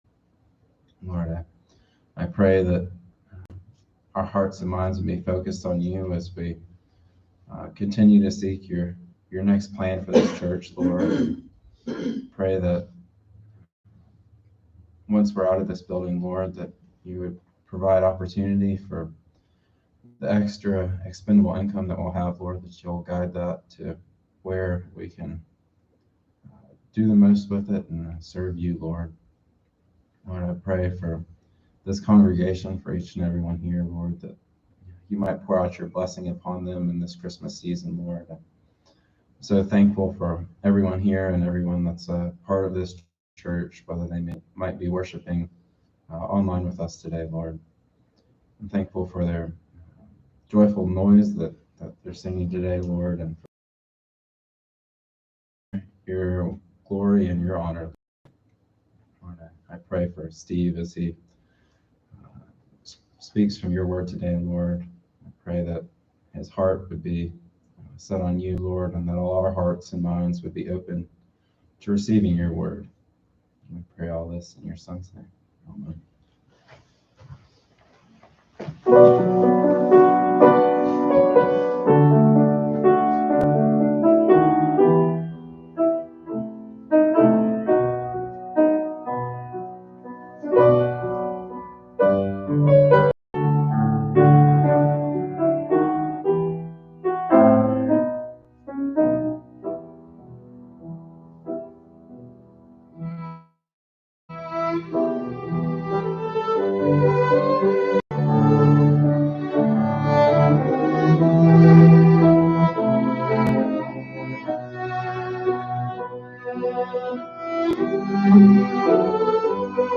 This sermon explores the challenging times before the birth of Jesus, focusing on the dark period of 400 years of silence and the corruption of leadership, while highlighting the unwavering faith of Zechariah and Elizabeth as God fulfills His promises.